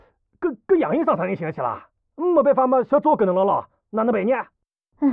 c02_6偷听对话_癞子_6_fx.wav